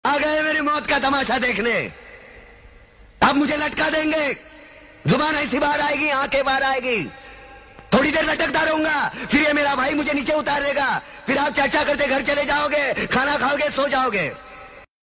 film dialogue aa gaye Meri